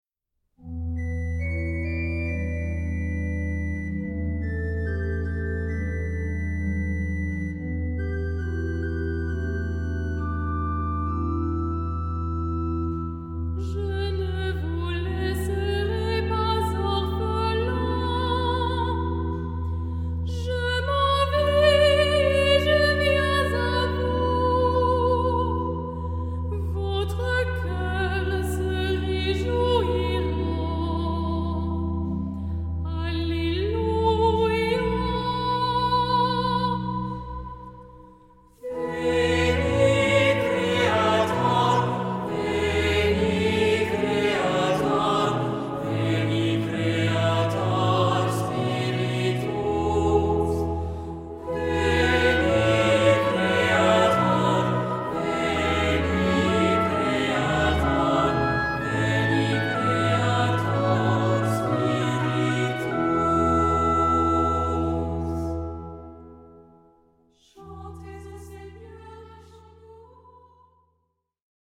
Genre-Style-Form: troparium ; Psalmody ; Sacred
Mood of the piece: collected
Instruments: Organ (1) ; Melody instrument (optional)
Tonality: E minor ; E major